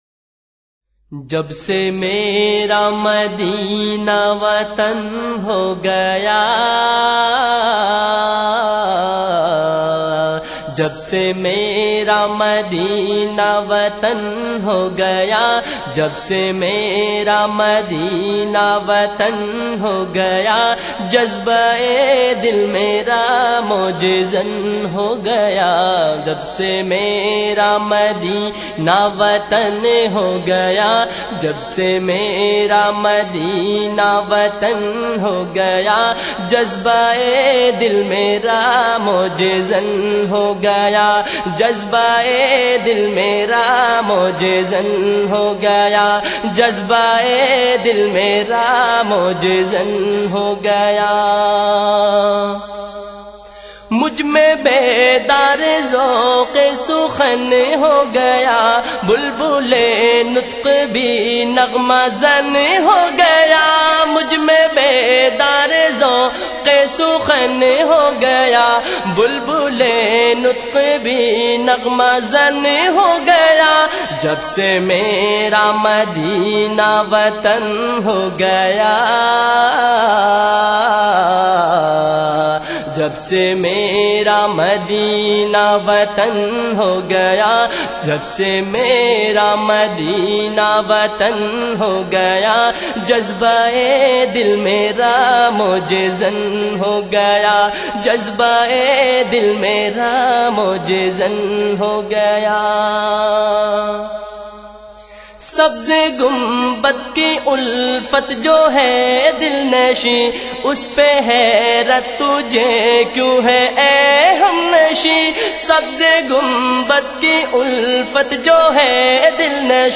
His amazing accent draws in his followers.